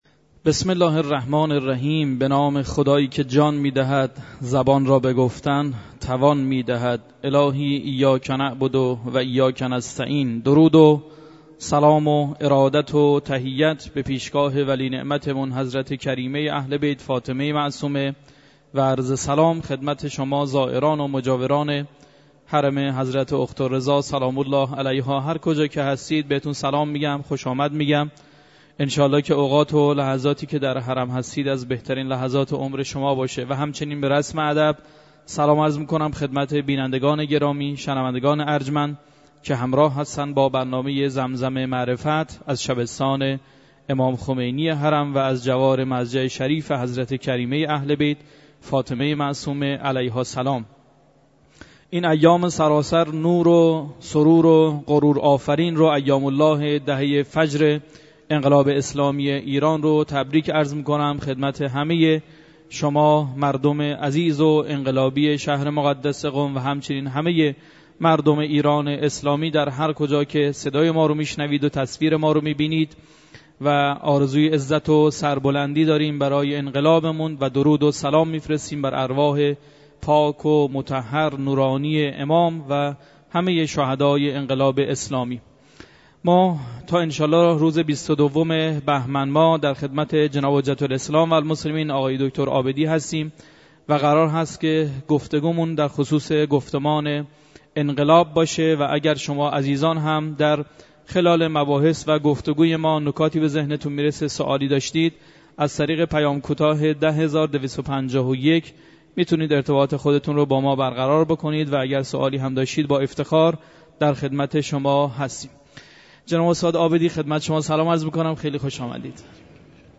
بیانات
در برنامه زمزم معرفت در حرم مطهر حرم معصومه (ع) درباره انقلاب اسلامی و امام خمینی(ره)